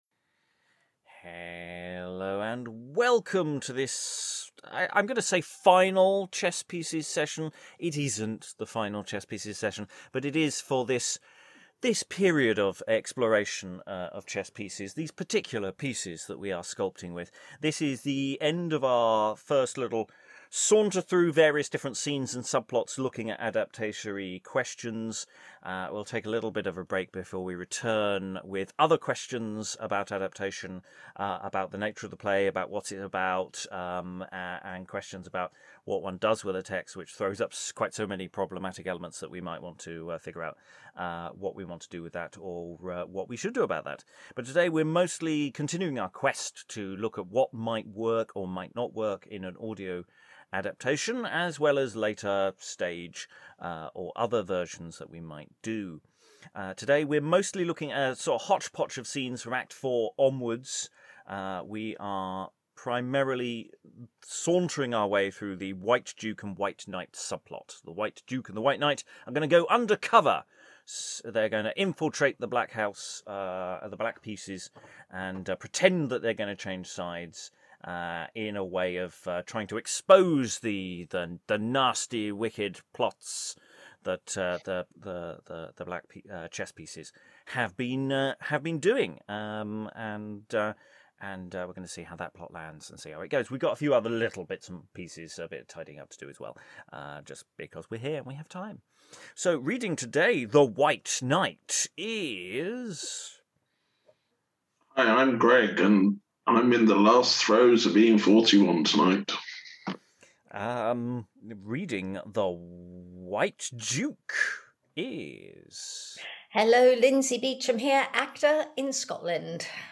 Chess Pieces: Exploring A Game at Chess by Thomas Middleton We focus on the closing scenes of the play, specifically the White Knight and Duke's attempt to infiltrate the Black House to uncover the truth about their plans. Reading combinations shift across the session